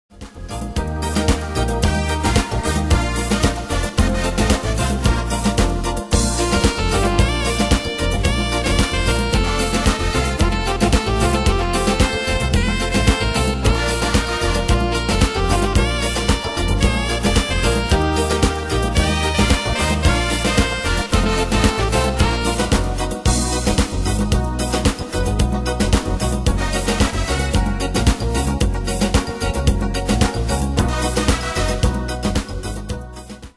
Style: PopFranco Ane/Year: 1984 Tempo: 112 Durée/Time: 3.20
Danse/Dance: Beguine Cat Id.
Pro Backing Tracks